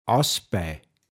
Wortlisten - Pinzgauer Mundart Lexikon